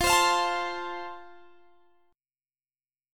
F Chord
Listen to F strummed